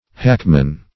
Hackmen (h[a^]k"men). The driver of a hack or carriage for public hire.